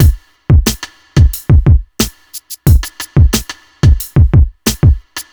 • 90 Bpm Drum Beat D Key.wav
Free drum beat - kick tuned to the D note.
90-bpm-drum-beat-d-key-4NC.wav